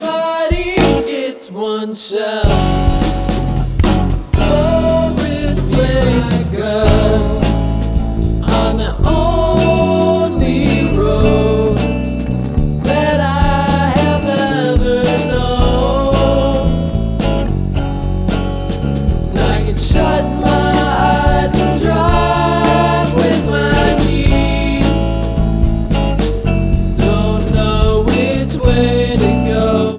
Drums
Bass